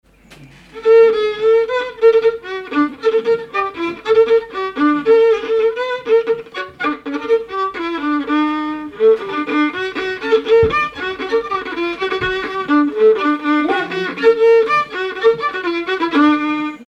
Air
Pièce musicale inédite